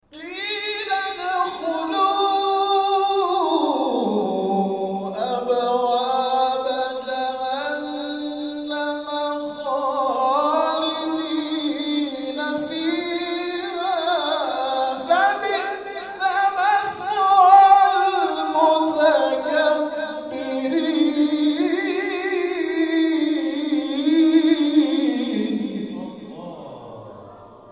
گروه جلسات و محافل: کرسی های تلاوت نفحات القرآن طی هفته گذشته در مساجد الزهراء(س) تهران و مسجد جامع امام رضا(ع) شهرری برگزار شد.